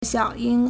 小鹰 (小鷹) xiǎoyīng
xiao3ying1.mp3